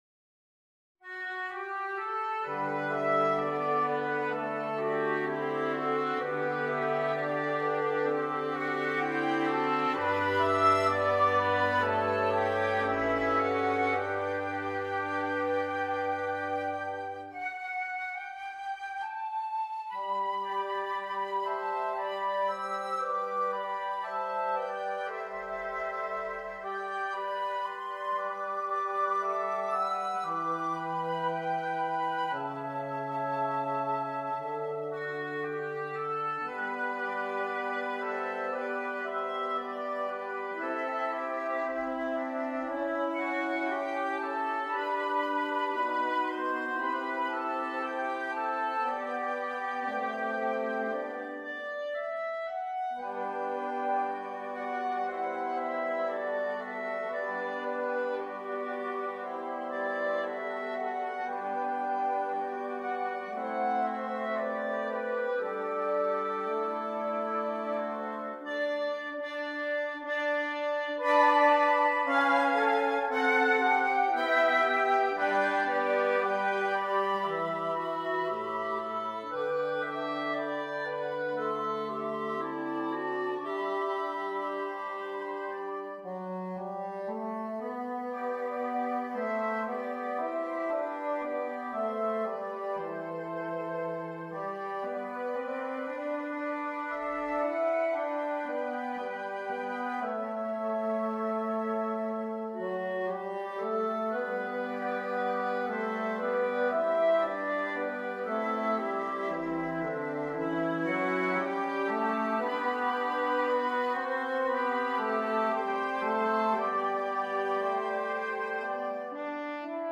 " a cherished traditional Irish folk tune
In this woodwind quintet arrangement